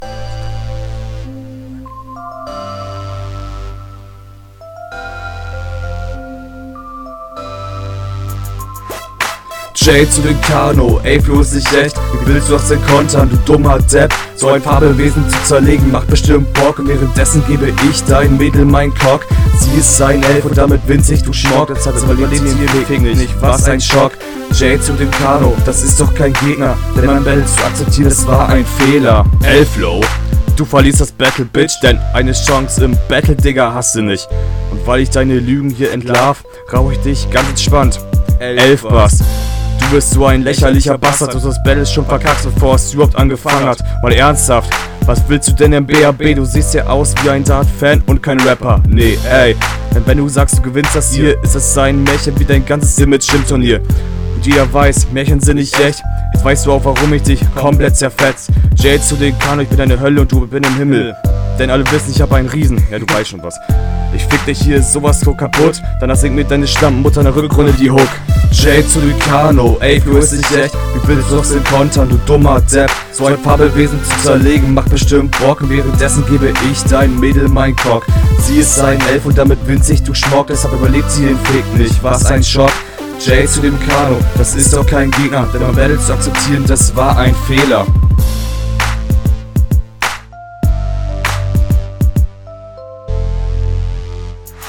Deine Doubles sind leider nicht aufeinander, darunter leider leider …